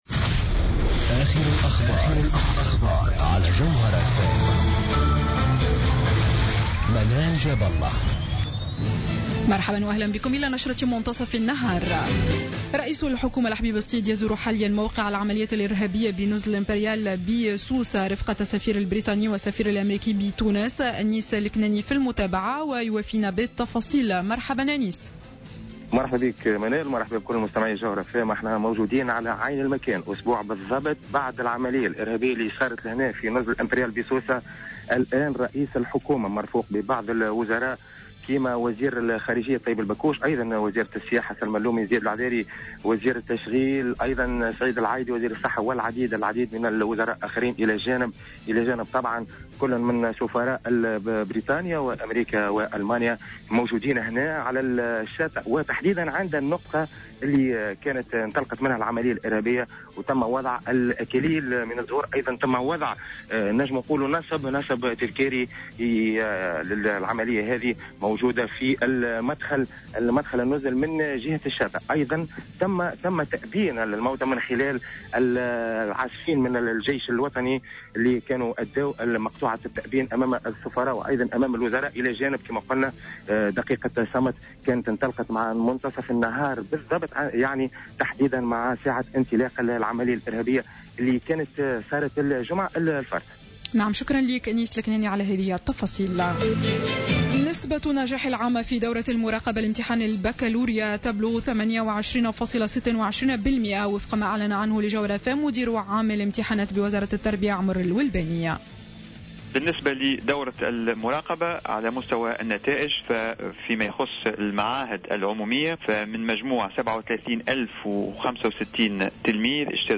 نشرة أخبار منتصف النهار ليوم الجمعة 03 جويلية 2015